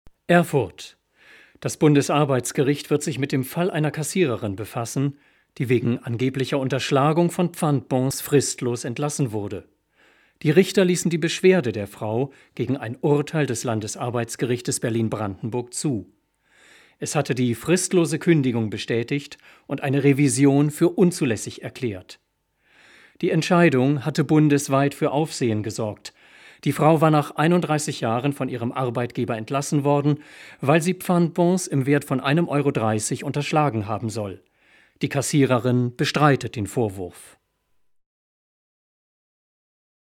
Deutscher Sprecher, Literaturlesungen, Kulturmoderation, Bariton
Sprechprobe: Industrie (Muttersprache):
Native German narrator for literature, readings, and presentation of cultural programmes